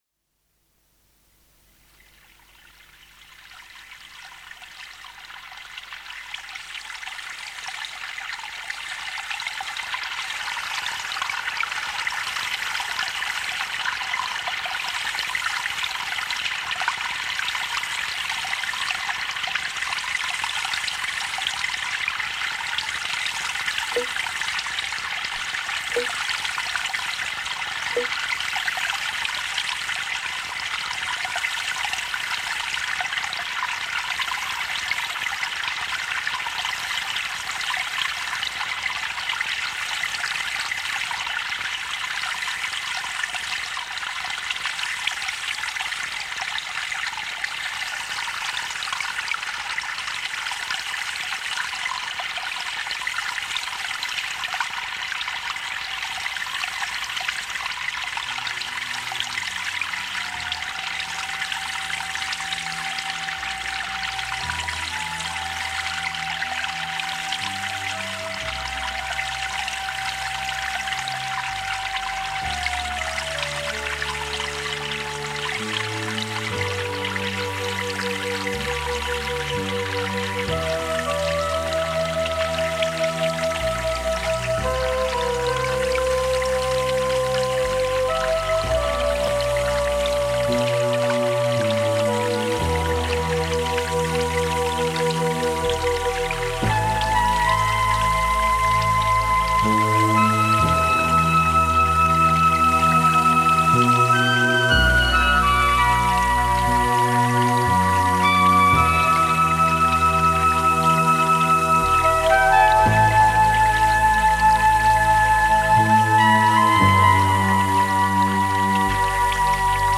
Obsahuje speciální psychoaktivní hudbu.